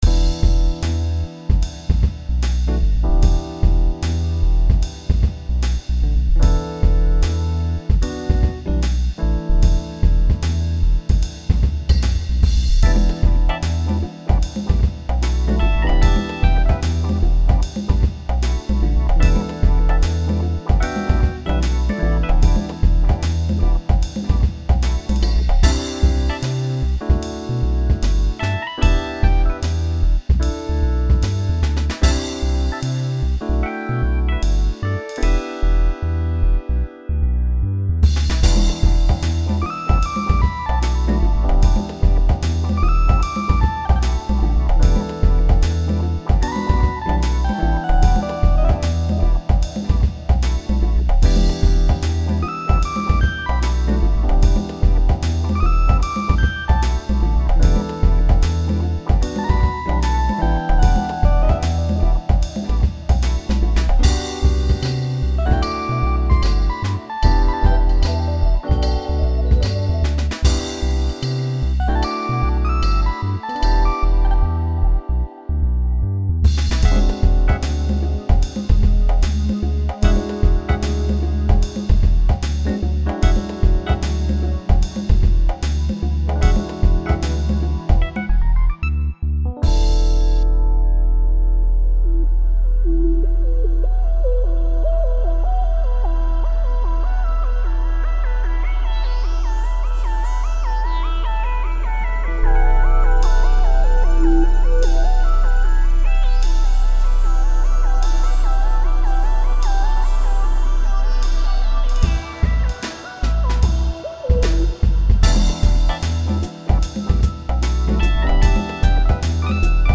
Download Song - Funk / Jazz / Groove / Blah